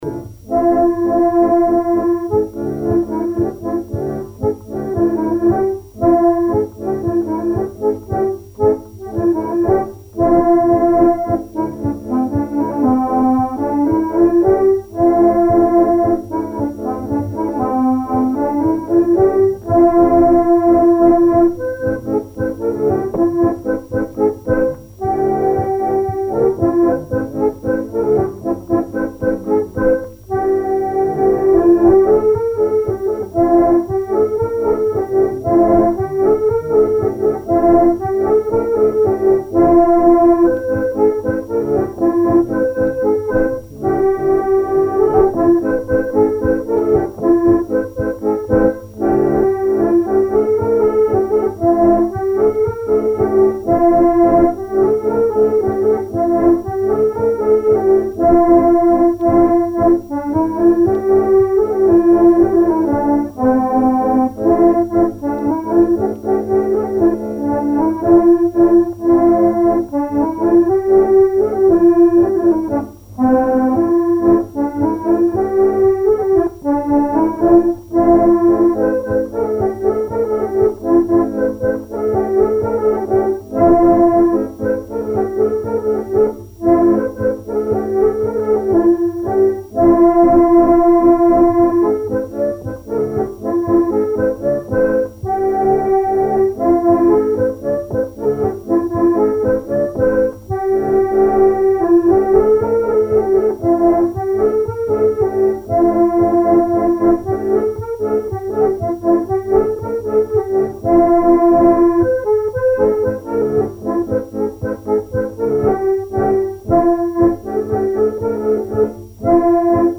Mémoires et Patrimoines vivants - RaddO est une base de données d'archives iconographiques et sonores.
Suite de branles de maraîchines
danse : branle : courante, maraîchine
Pièce musicale inédite